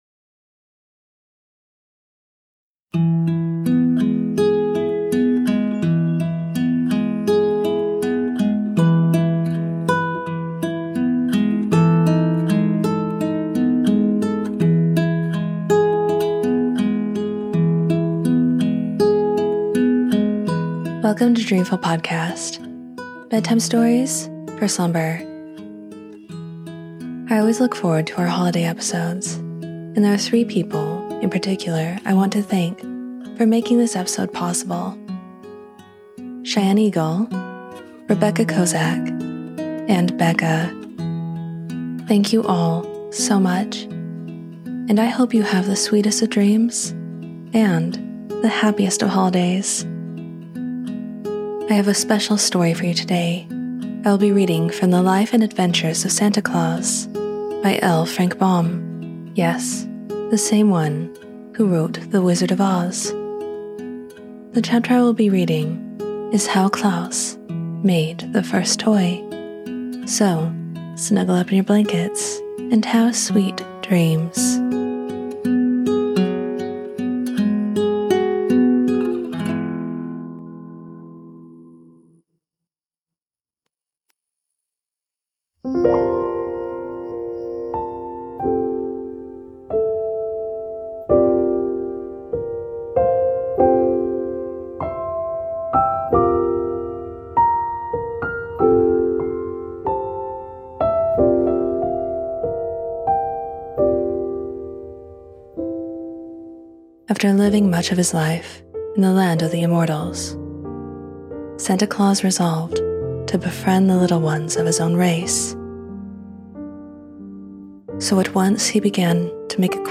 We read from L. Frank Baum’s The Life and Adventures of Santa Claus and follow a young Claus as he crosses a valley, meets neglected children, and learns how simple acts can bend a hard world toward joy.